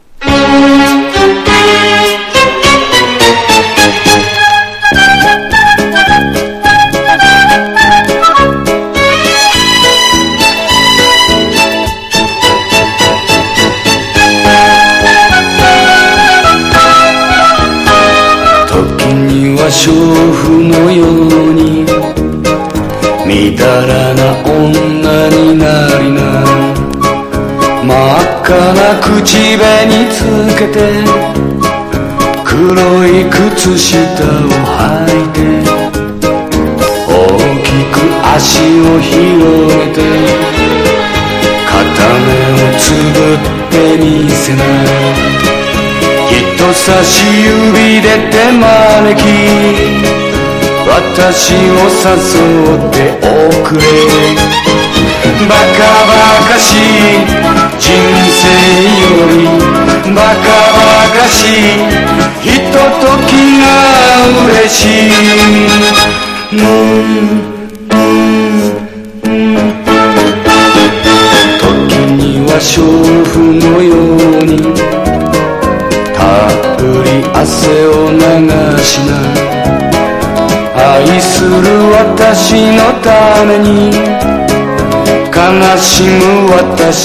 独特な短調でヒットした
和モノ / ポピュラー